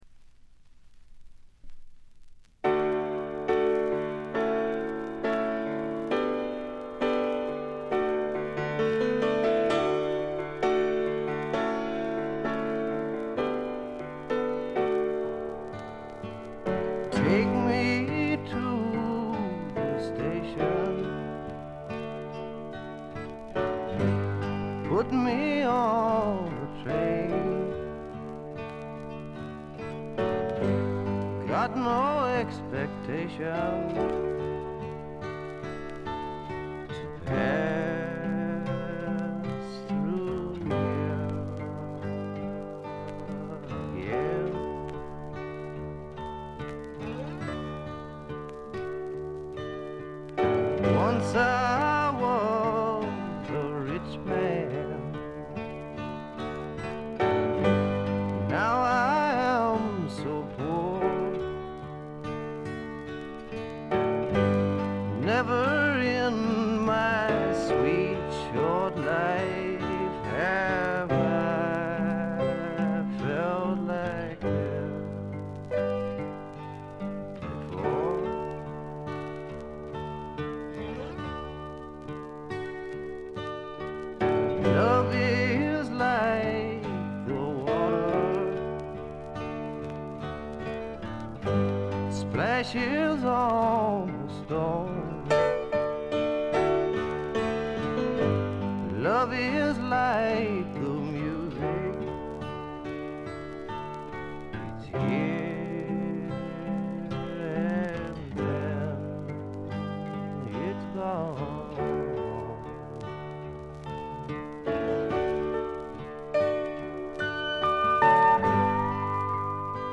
軽微なバックグラウンドノイズ、チリプチ程度。
試聴曲は現品からの取り込み音源です。